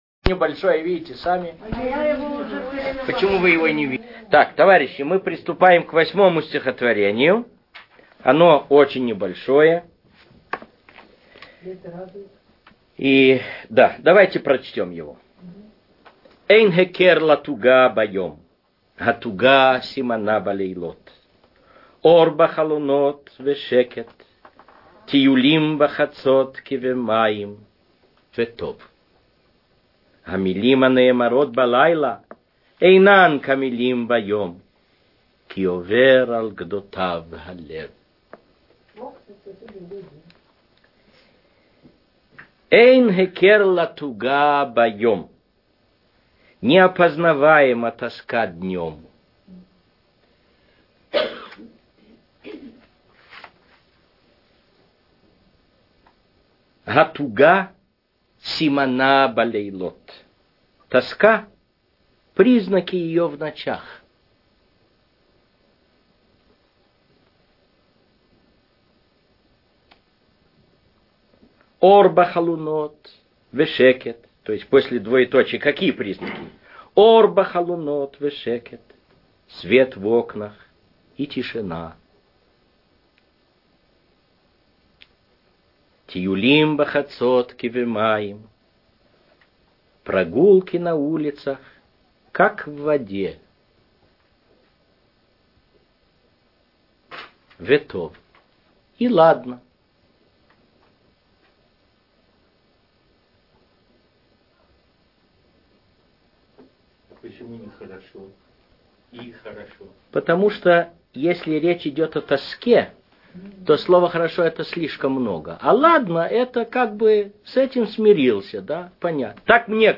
Аудиокомментарий